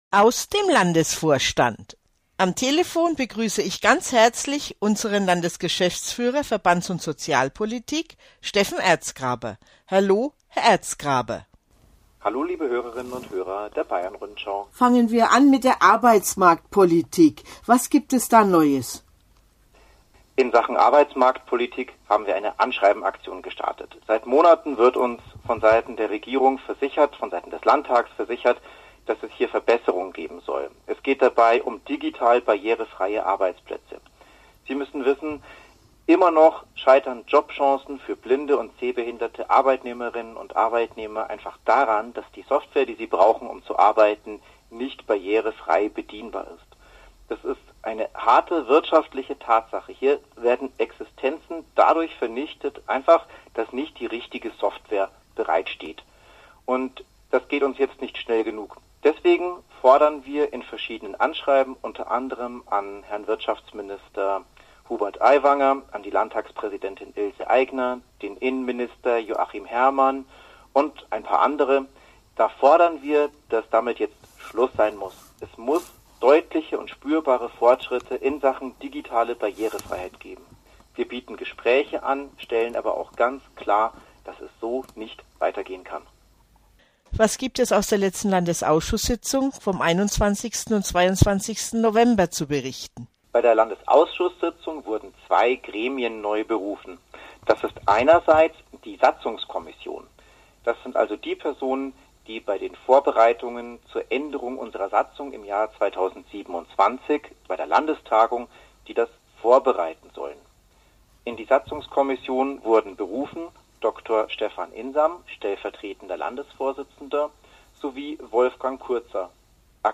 Dieser Podcast ist das Mitgliedermagazin des BBSB. Alle zwei Monate erscheint eine neue Ausgabe, die Hintergrundberichte, Interviews, sowie Wissenswertes aus Bayern und darüber hinaus liefert.